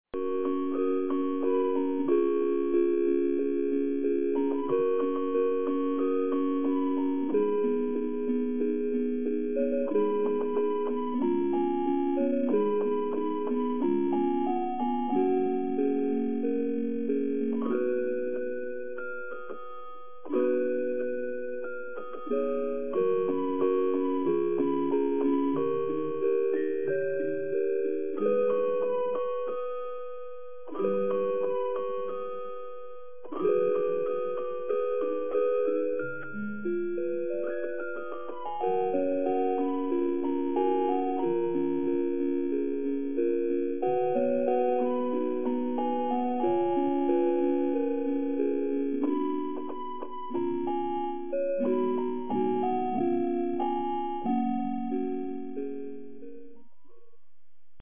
2026年03月23日 11時31分に、津市より栗葉、榊原、香良洲へ放送がありました。
放送音声